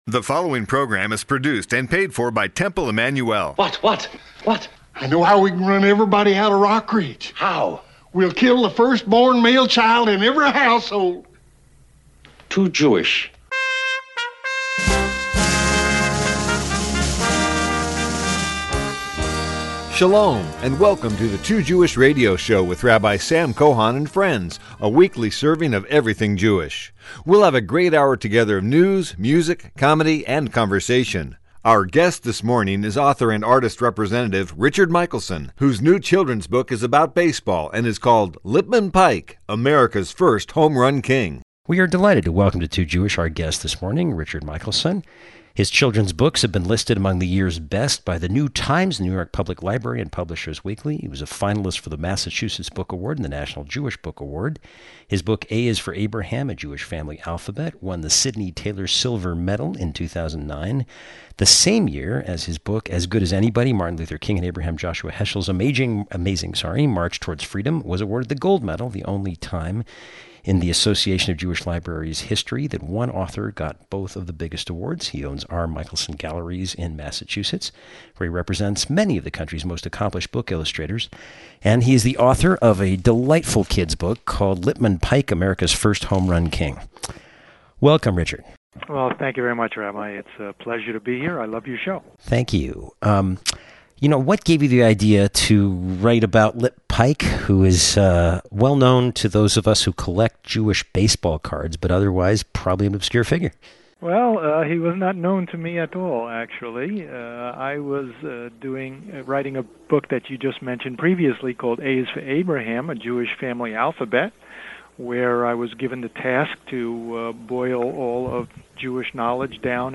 Too Jewish interview